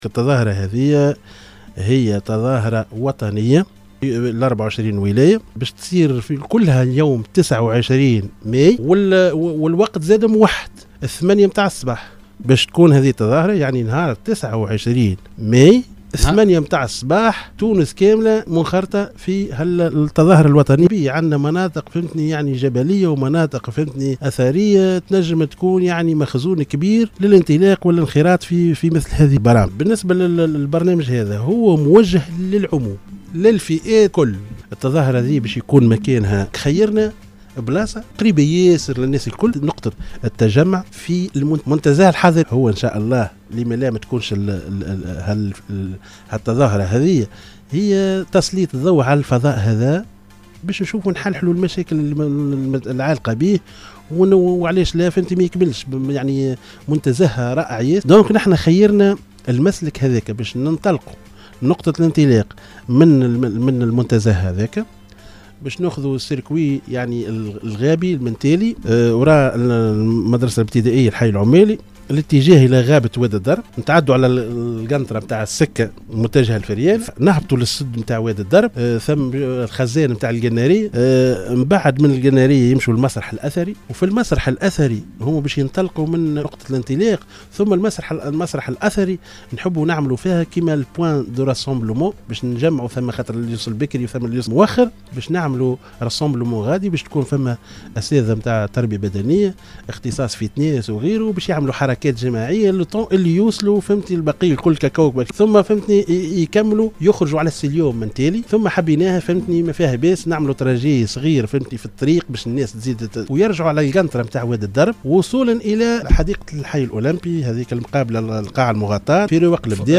في تصريح لإذاعة السيليوم أف أم اليوم الأربعاء 25 ماي 2022 أثناء تدخّله ببرنامج القصرين و أحوالها